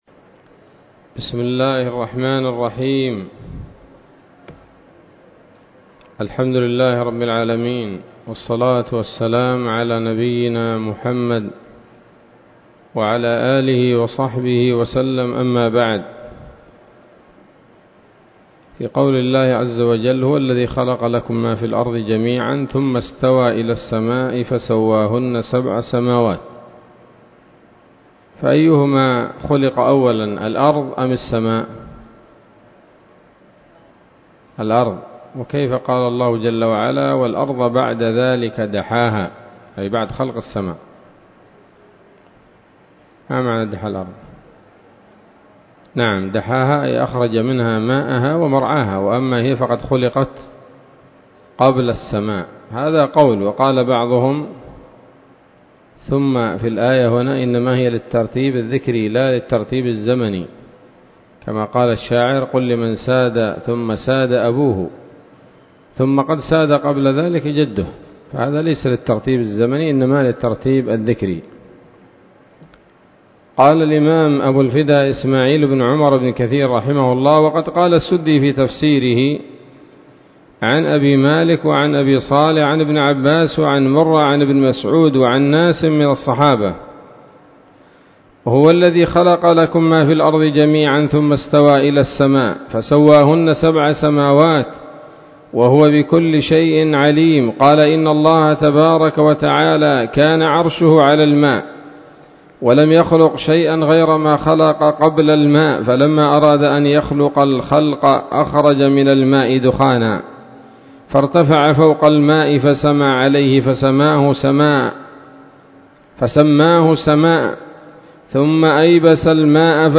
الدرس الثاني والثلاثون من سورة البقرة من تفسير ابن كثير رحمه الله تعالى